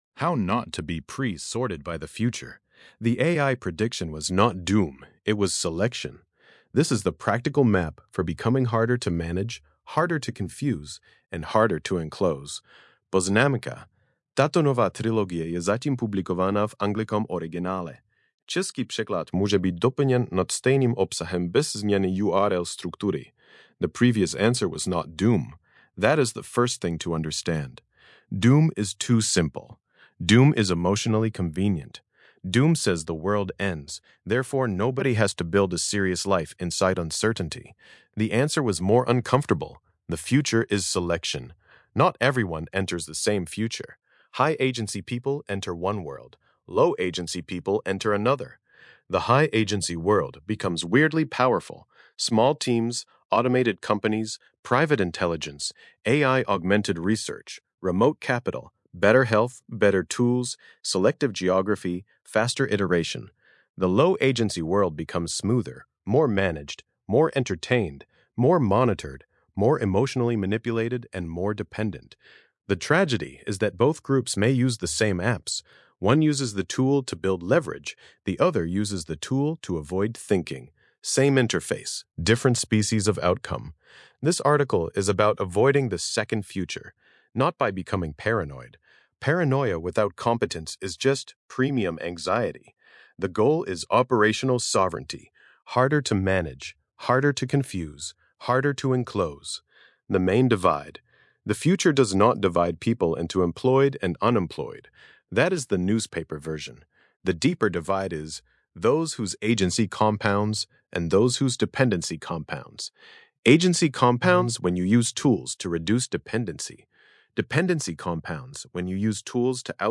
Podcastová audio verze této eseje, vytvořená pomocí Grok Voice API.